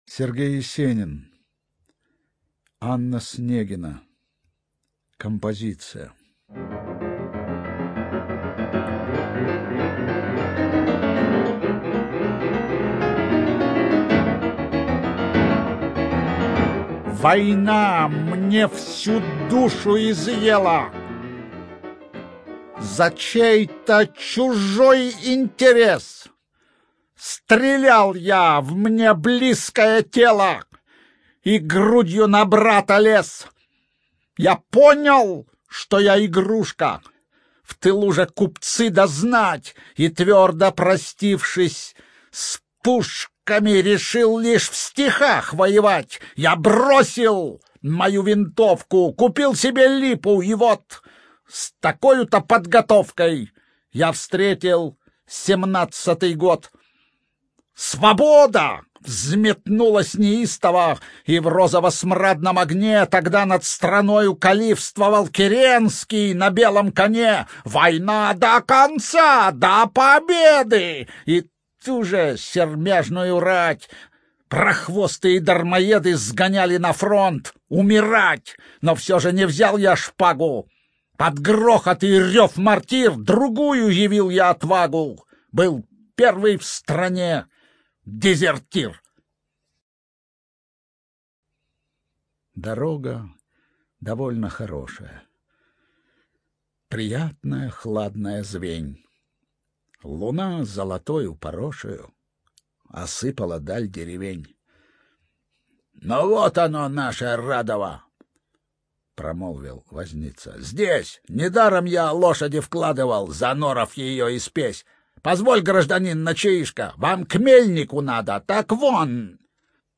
ЧитаетЮрский С.